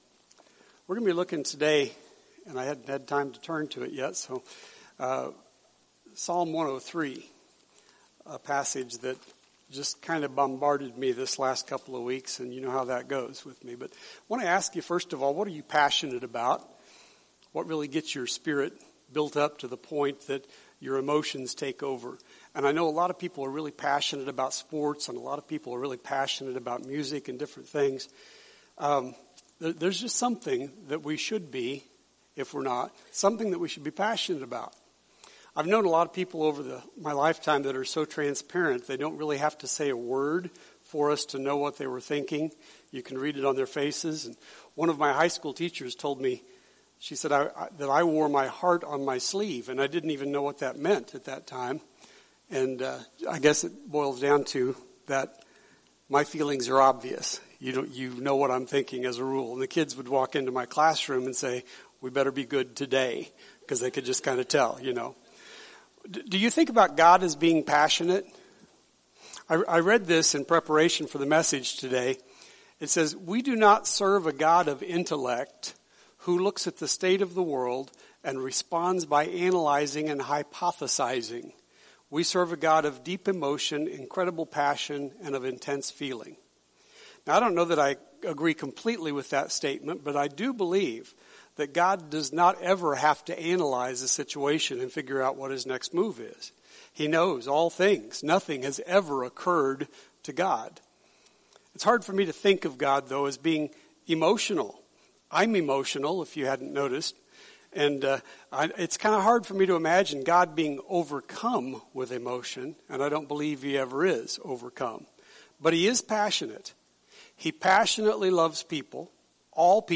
Sermon Archive – Immanuel Baptist Church